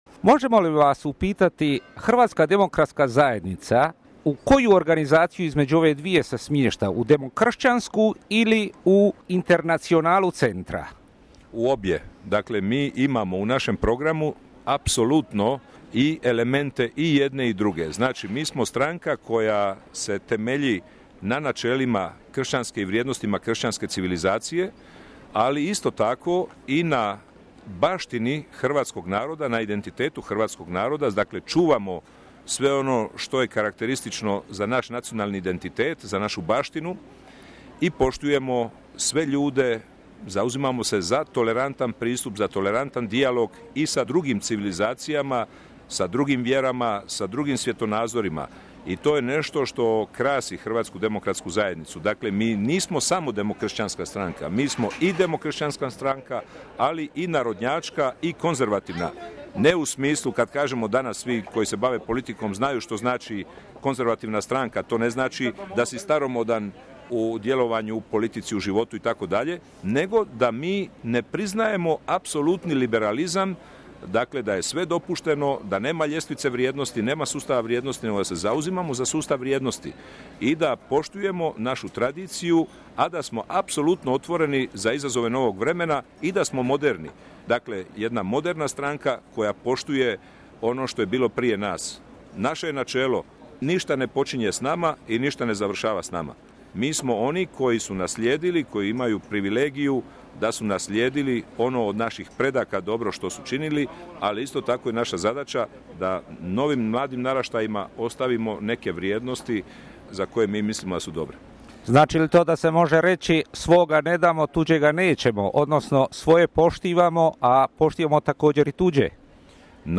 Raygovor s dr. Ivom Sanaderom